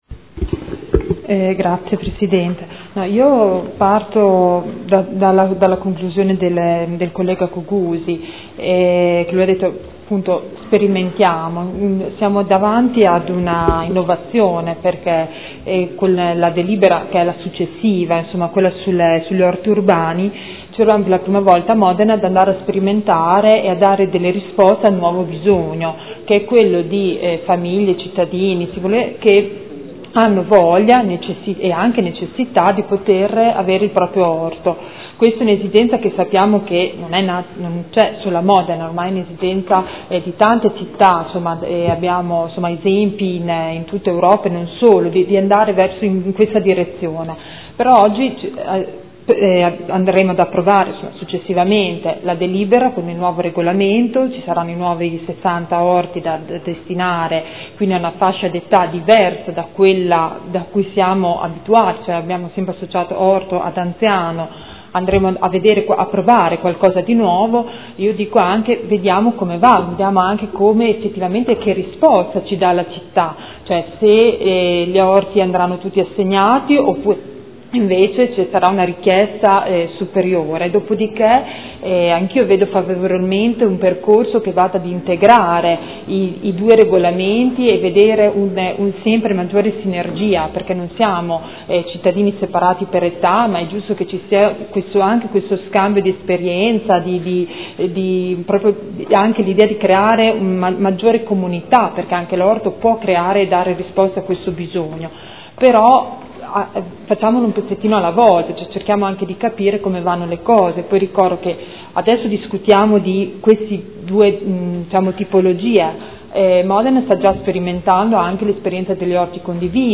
Grazia Baracchi — Sito Audio Consiglio Comunale